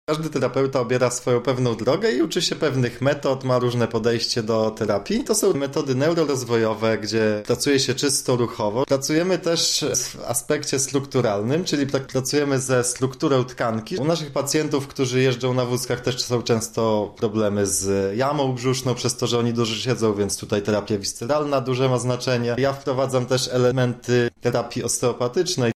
mówi fizjoterapeuta